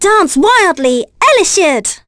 Ophelia-Vox_Skill5.wav